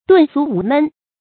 遁俗無悶 注音： ㄉㄨㄣˋ ㄙㄨˊ ㄨˊ ㄇㄣˋ 讀音讀法： 意思解釋： 見「遁世無悶」。